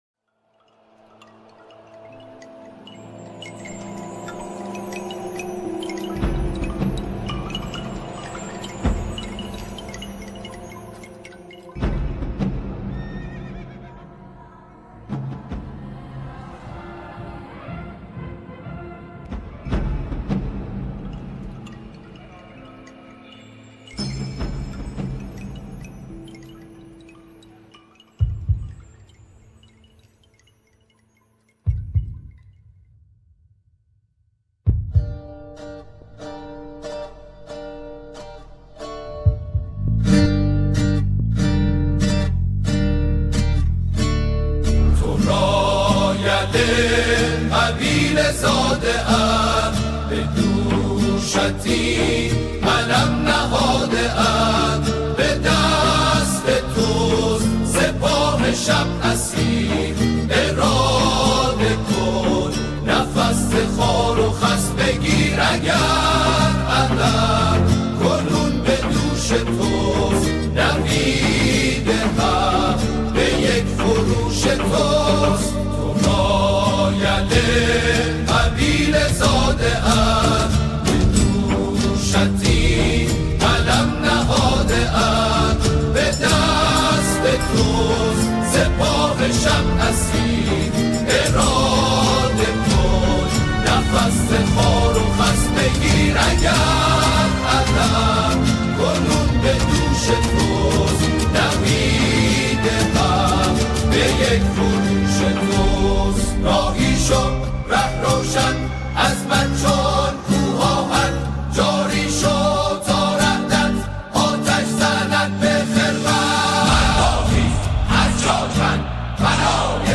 یک اثر حماسی، انقلابی و متفاوت
ژانر: سرود